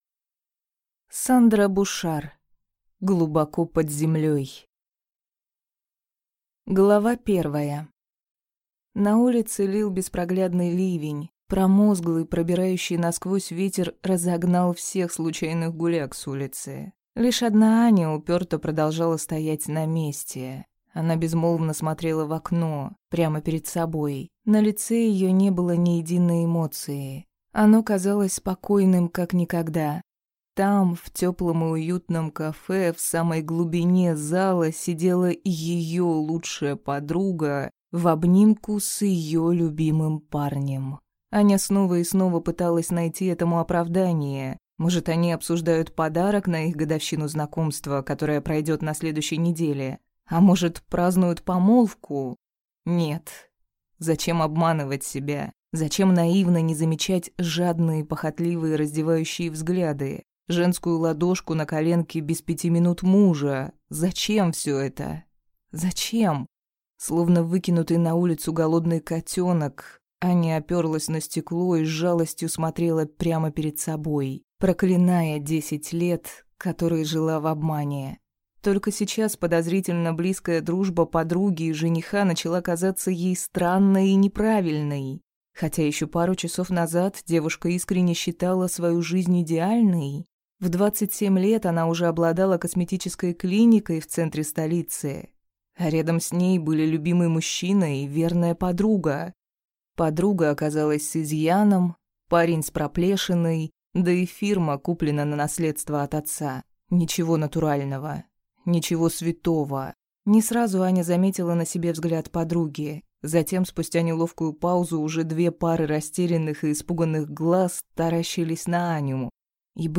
Аудиокнига Глубоко под землей | Библиотека аудиокниг